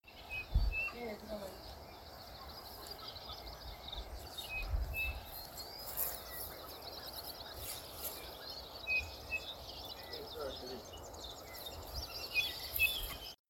Striped Cuckoo (Tapera naevia)
Province / Department: Buenos Aires
Detailed location: CEAMSE - Complejo Ambiental Villa Domínico
Condition: Wild
Certainty: Recorded vocal